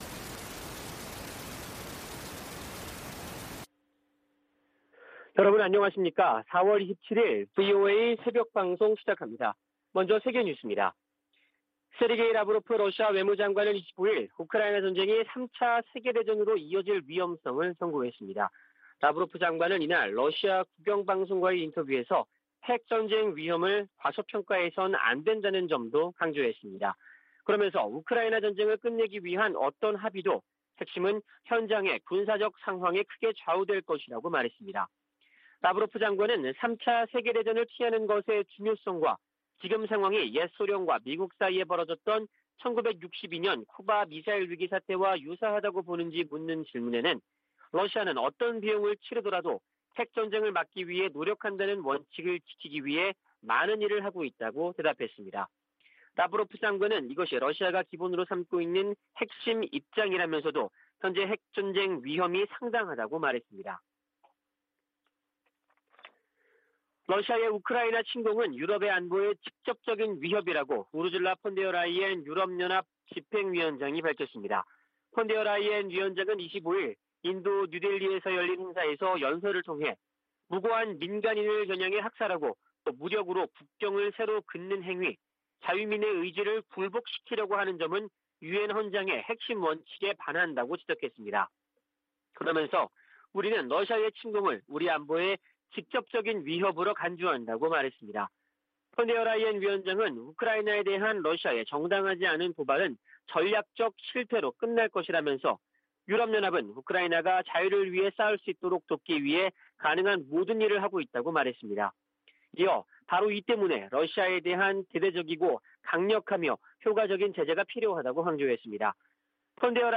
VOA 한국어 '출발 뉴스 쇼', 2022년 4월 27일 방송입니다. 북한이 25일 핵 무력을 과시하는 열병식을 개최했습니다.